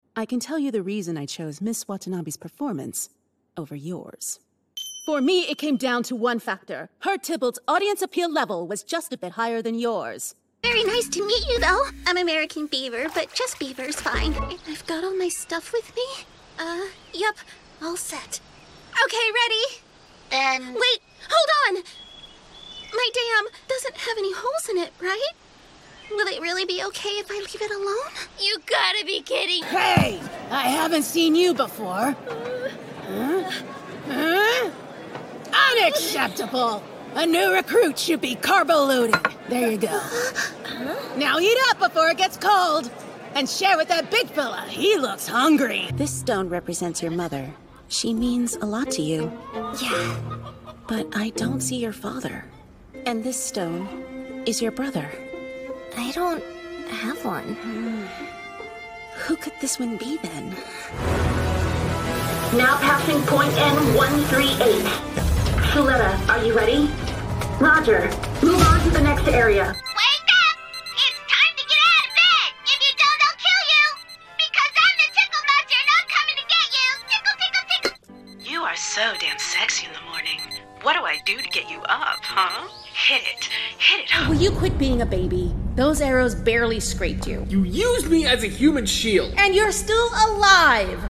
Bold and cinematic in their expression with a knack for comedic timing and a voice described as earthy, vulnerable, and nuanced
Animation